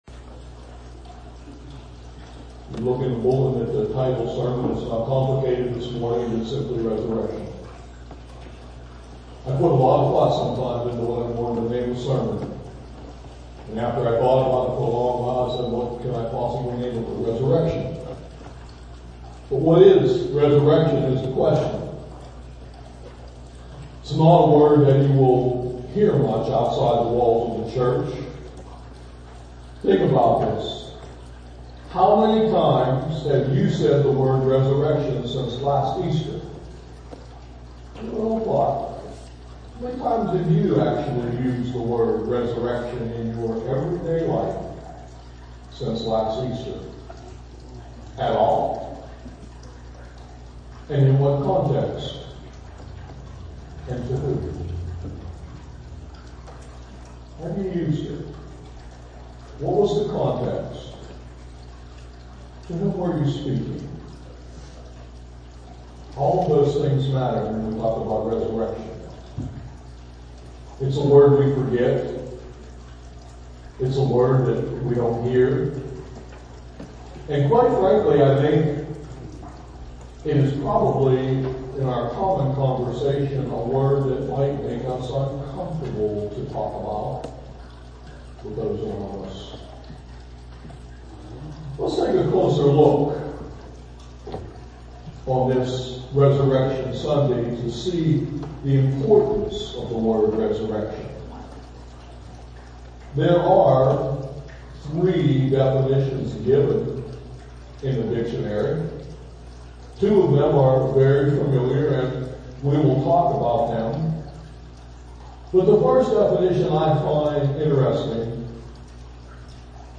MORNING MESSAGE TEXT: John 20:1-18